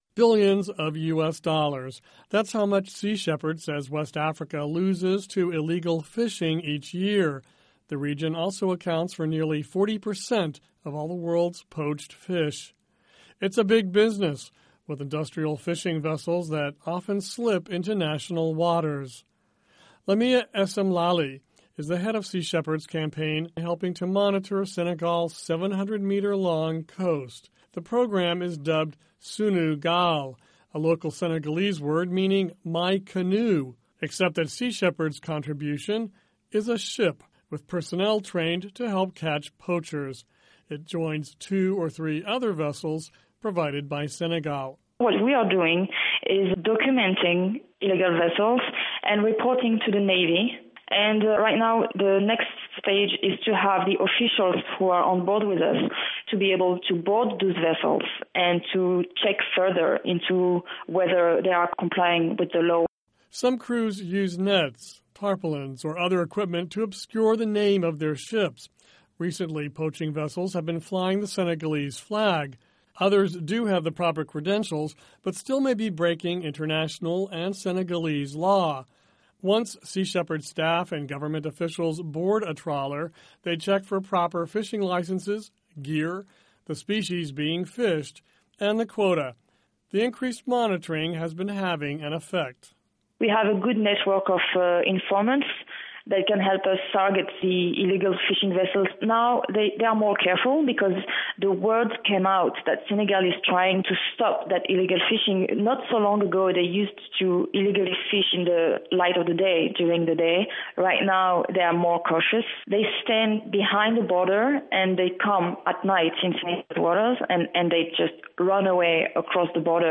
Listen to report of Sea Shepherd campaign in Senegal